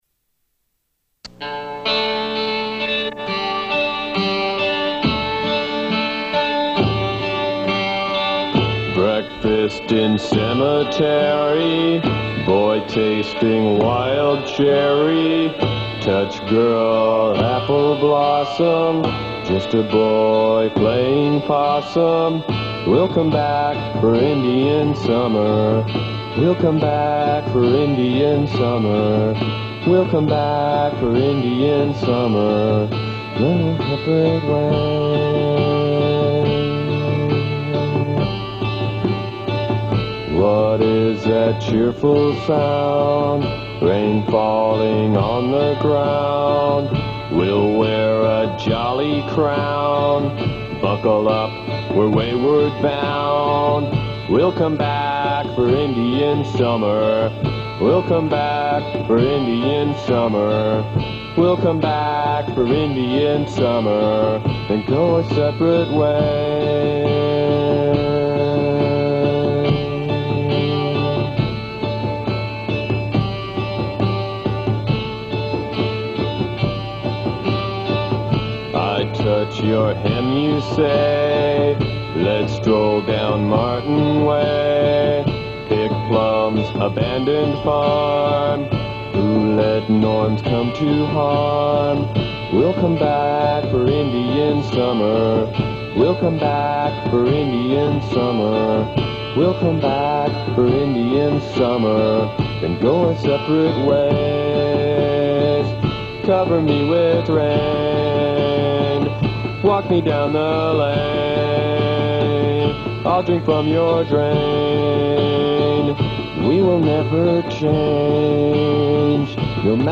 Great lo-fi indie pop.